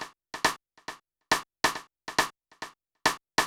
DELAY SD  -L.wav